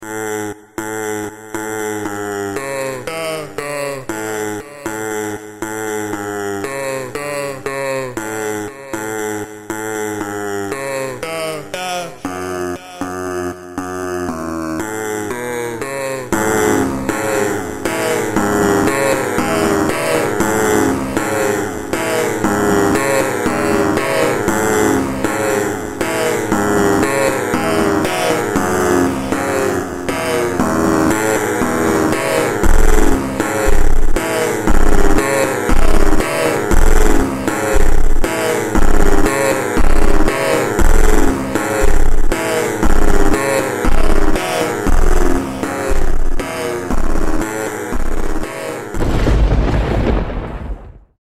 mega slowed